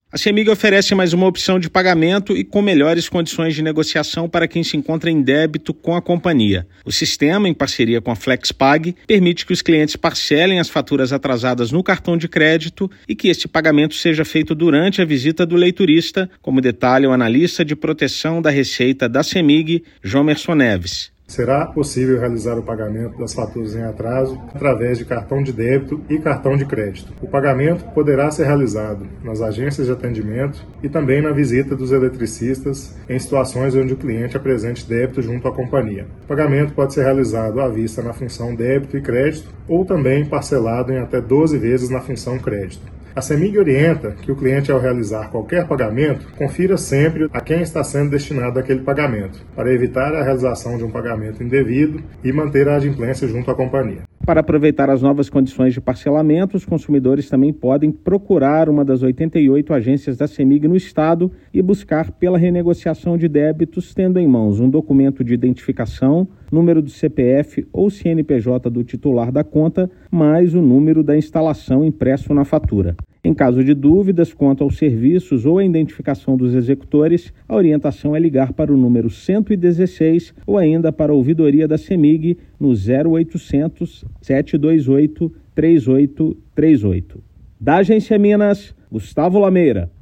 Parceria com a Flexpag permite que consumidores quitem suas faturas em atraso utilizando cartões de crédito ou débito. Ouça matéria de rádio.